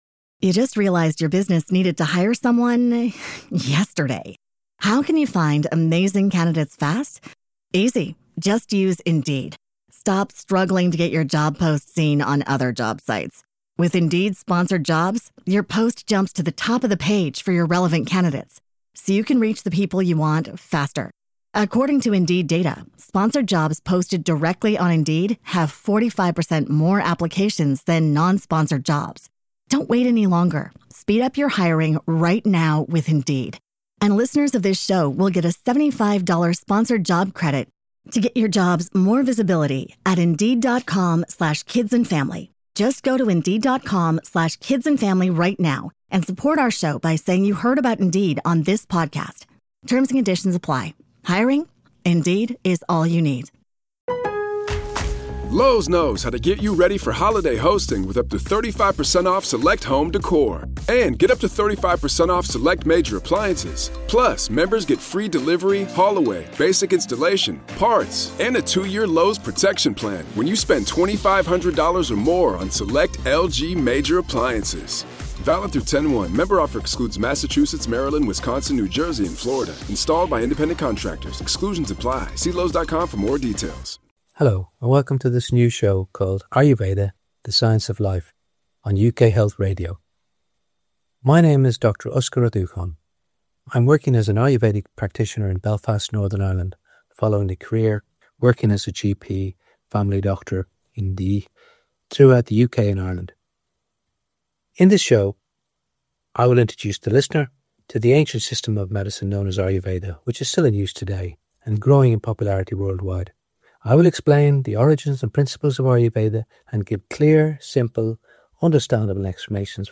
I'll interview therapists and health experts who share a holistic view of wellness.